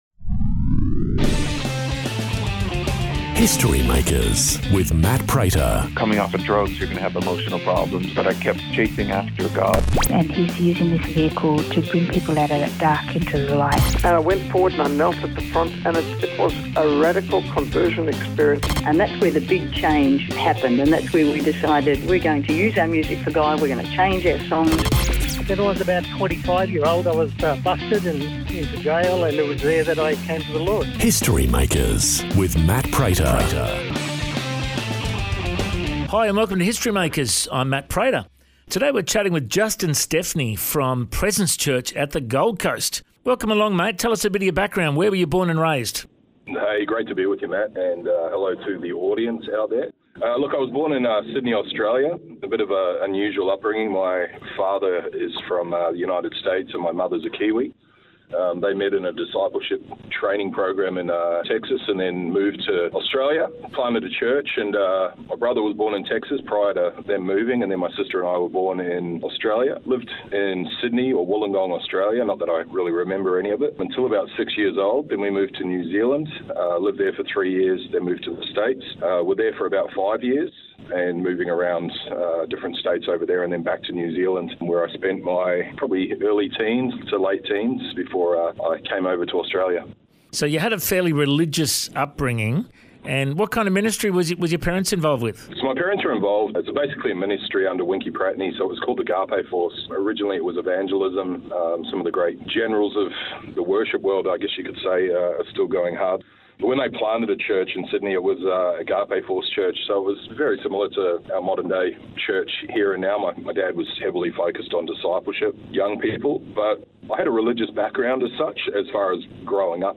In this interview, he shares about his faith journey & some lessons we can learn from the early Church, listen in to his story!